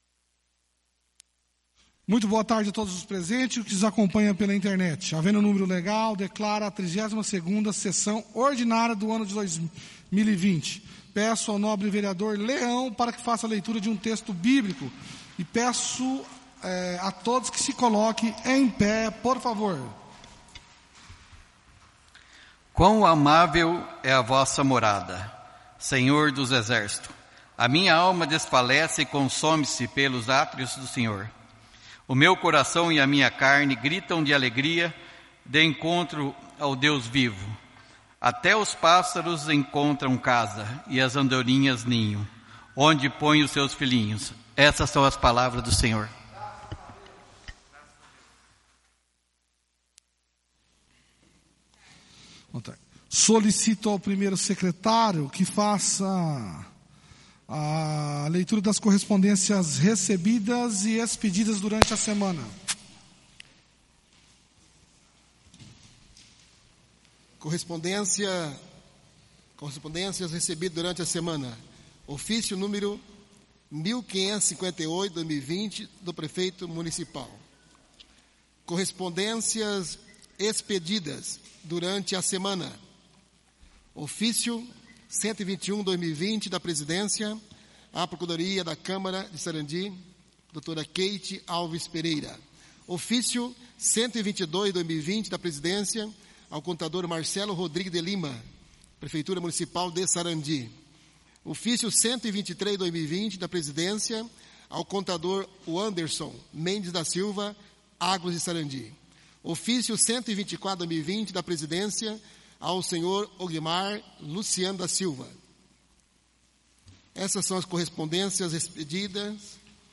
O Presidente da Câmara Municipal de Sarandi-PR. Sr. Eunildo Zanchim, verificando a existência de quórum legal dá início à 32ª Reunião Ordinária do dia 20/10/2020.
A convite do Senhor Presidente, o edil CARLOS ROBERTO FALASCHI, procedeu à leitura de um texto bíblico.